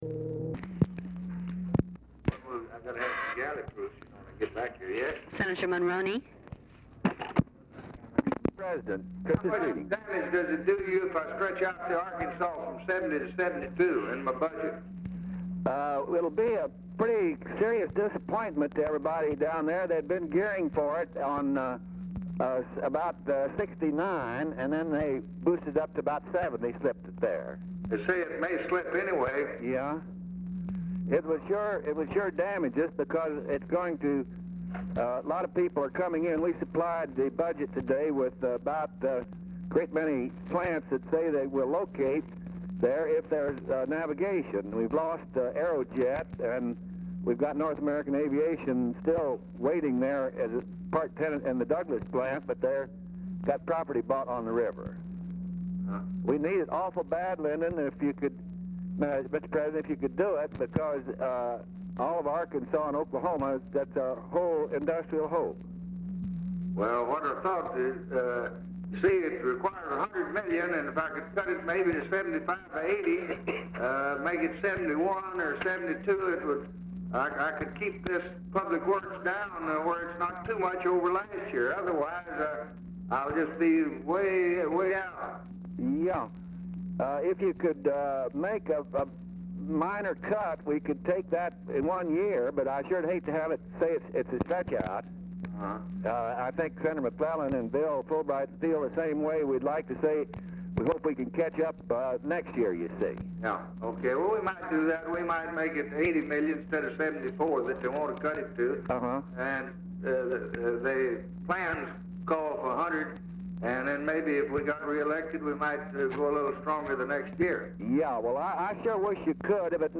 Telephone conversation # 634, sound recording, LBJ and MIKE MONRONEY, 12/21/1963, 6:20PM
Format Dictation belt
Location Of Speaker 1 Oval Office or unknown location